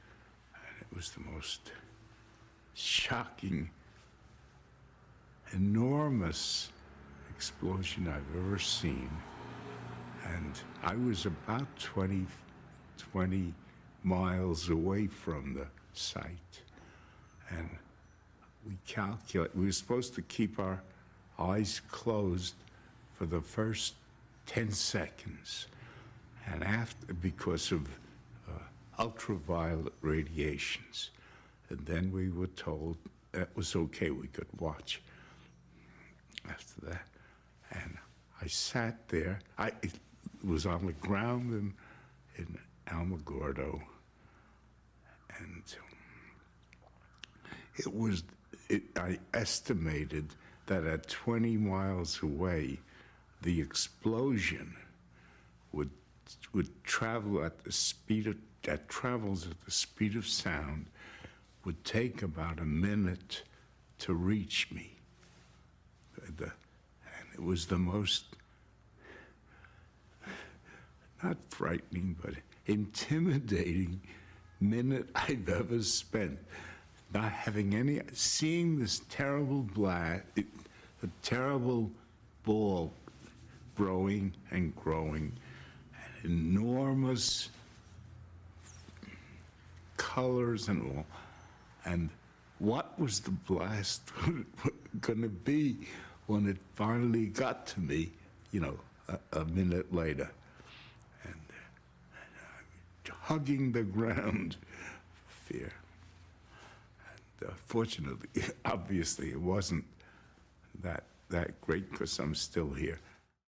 In our search for understanding the role of sound in life with the bomb, we must turn to another form of aural record - that of eyewitness accounts. Here are a few examples, excerpted from longer oral histories: